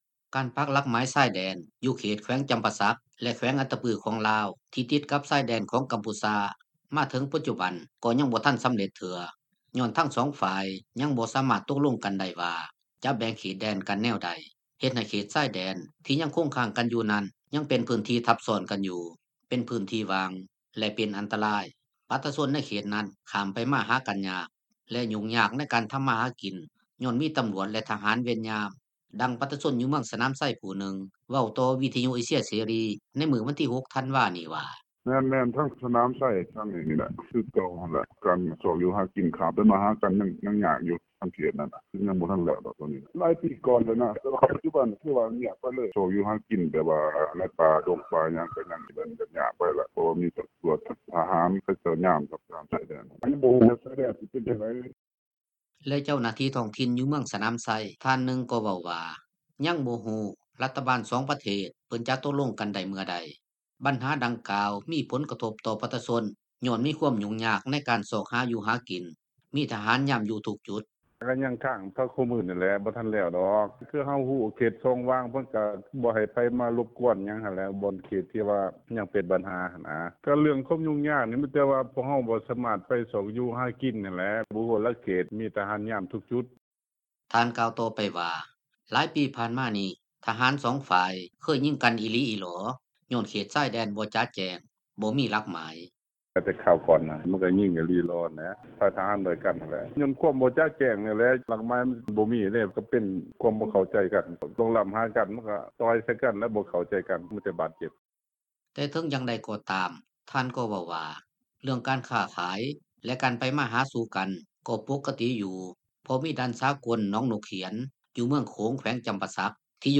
ດັ່ງປະຊາຊົນຢູ່ເມືອງສະໜາມໄຊ ຜູ້ໜຶ່ງເວົ້າຕໍ່ວິທຍຸເອເຊັຽເສຣີ ໃນມື້ວັນທີ 6 ທັນວານີ້ວ່າ: